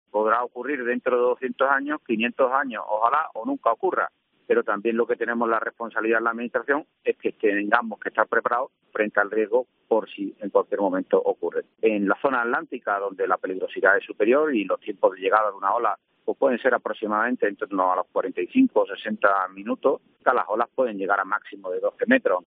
Antonio Sanz, consejero de Presidencia de la Junta de Andalucía habla del Plan de Emergencia ante el riesgo de